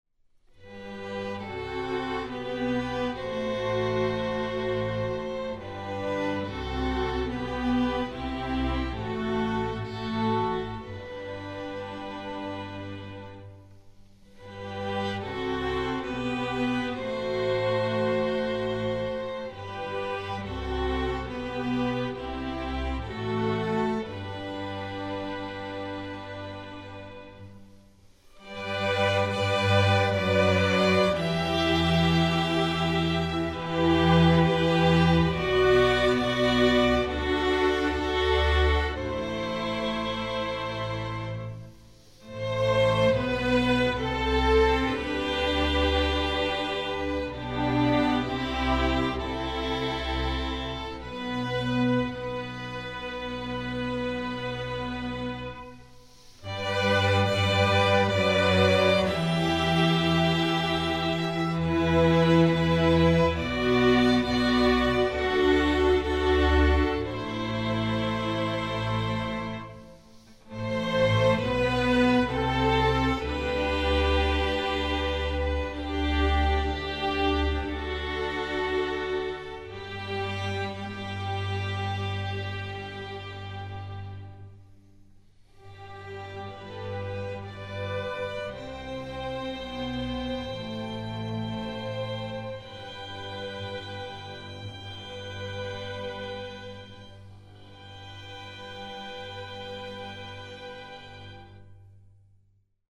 classical, children
Piano accompaniment part: